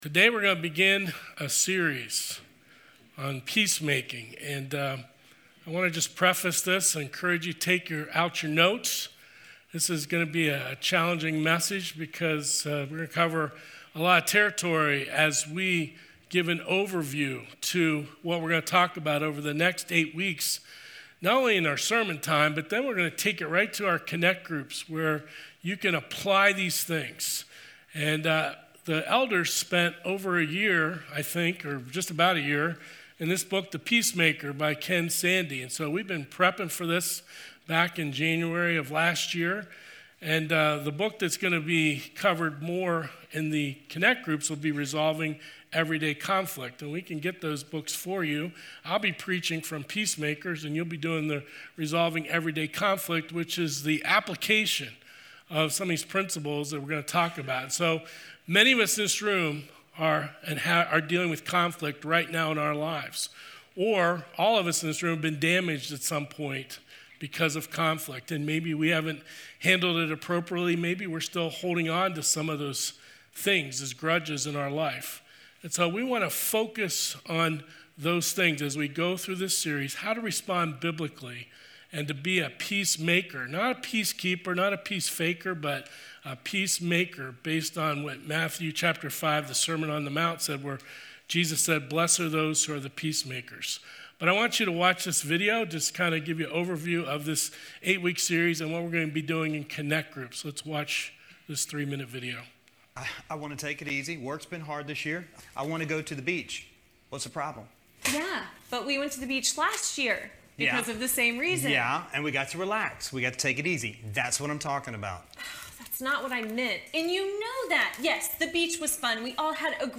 Sermons | Pleasant View Baptist Church